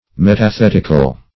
Search Result for " metathetical" : The Collaborative International Dictionary of English v.0.48: Metathetic \Met`a*thet"ic\, Metathetical \Met`a*thet"ic*al\, a. Of or pertaining to metathesis.